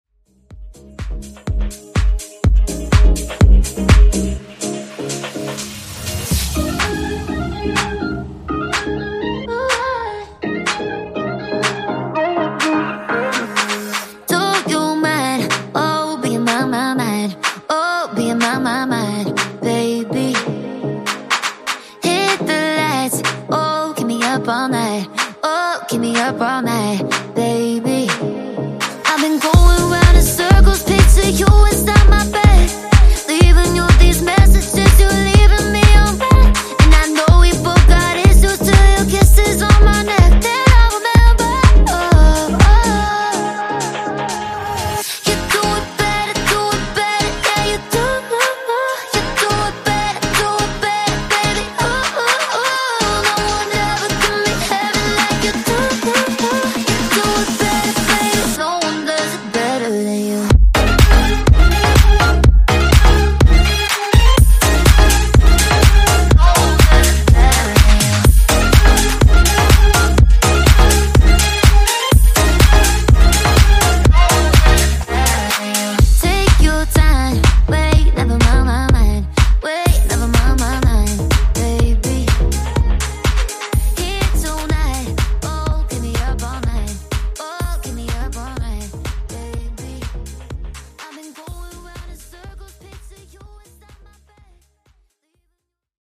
Genres: RE-DRUM , REGGAETON
Clean BPM: 100 Time